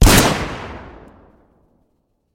shot1.mp3